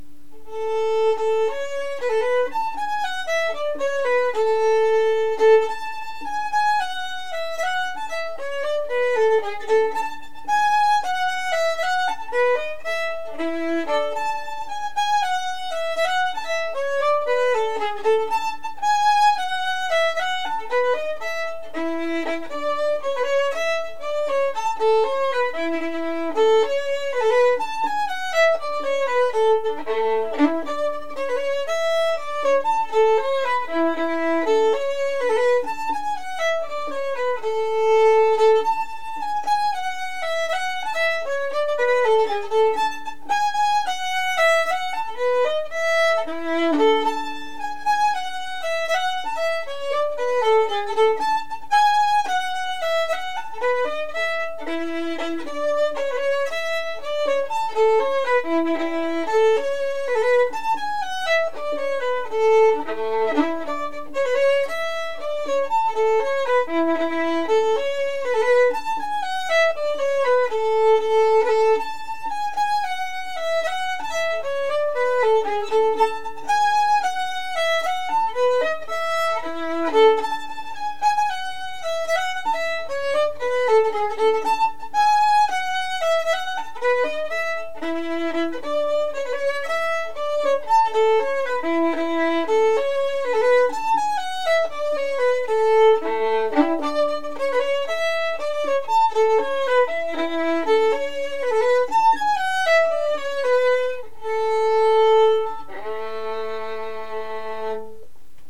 Each link connects to a Morris Dance tune, to play for practice ...
. . . English Country Dances: